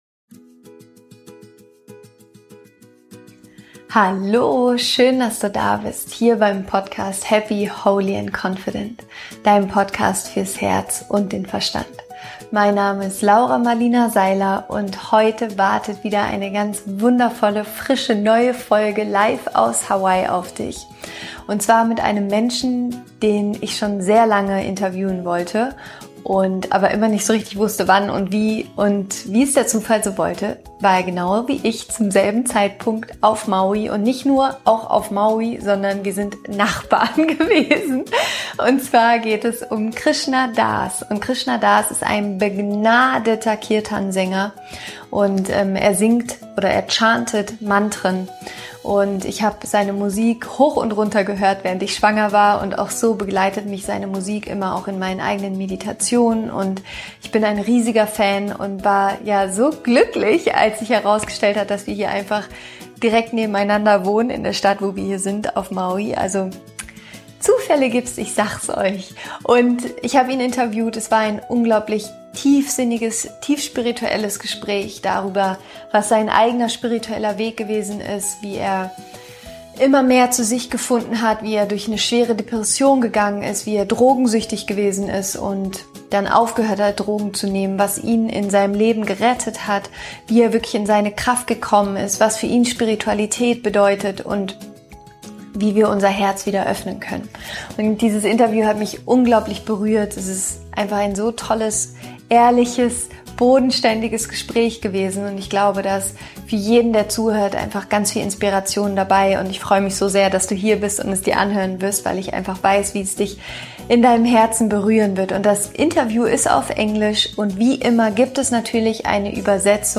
Warum wir bedingungslose Liebe nur in uns selbst finden konnen Interview Special mit Krishna Das